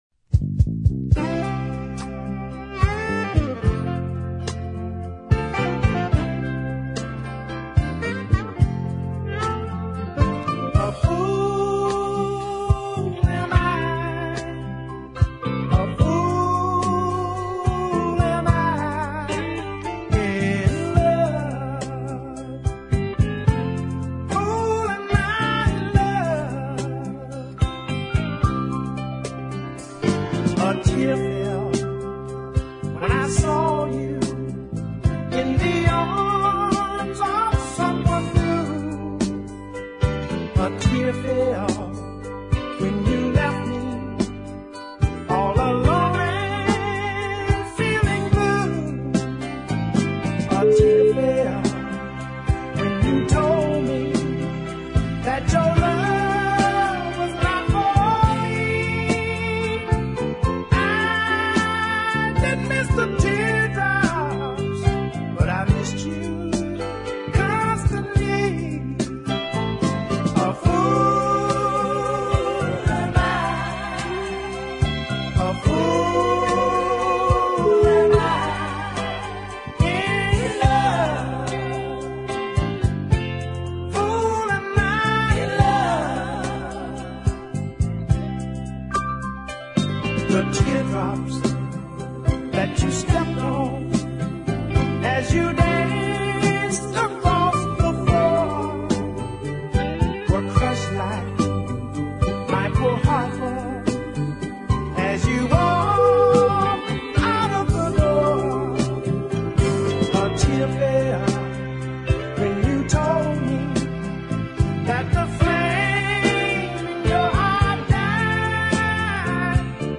But even better are the waltz time Listen
lovely country ballad